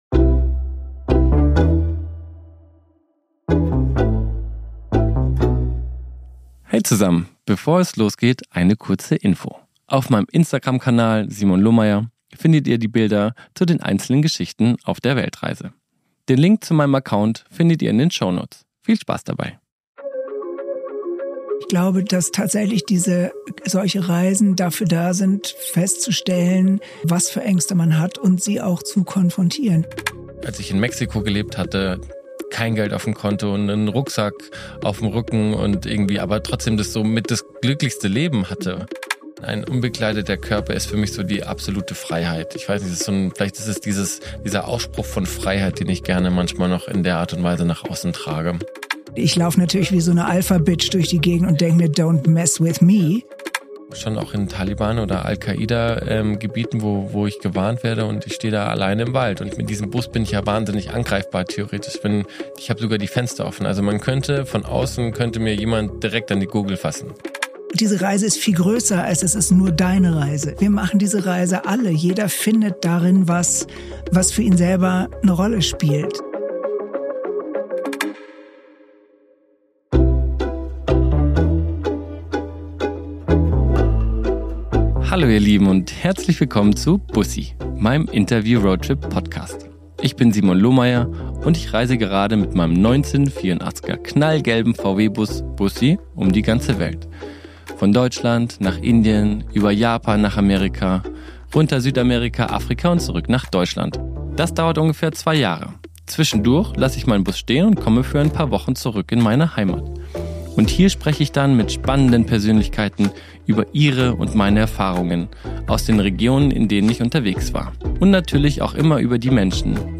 ~ BUSSI - Ein Interview-Roadtrip Podcast